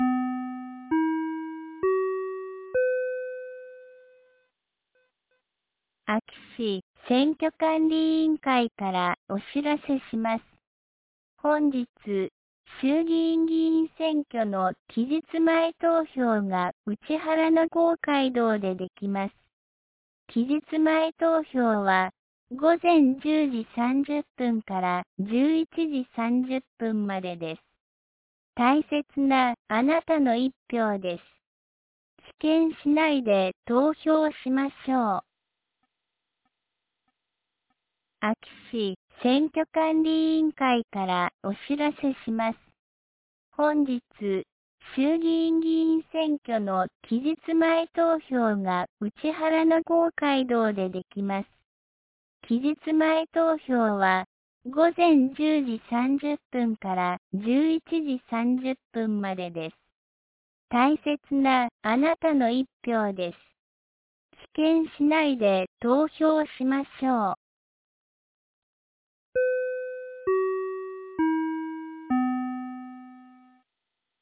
2026年02月05日 09時01分に、安芸市より井ノ口へ放送がありました。